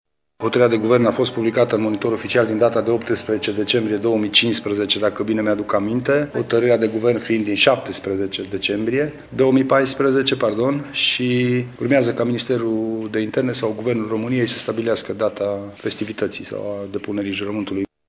Lucian Goga a declarat pentru Radio Tîrgu-Mureş că aşteaptă să fie anunţat de Minister când va fi organizată ceremonia: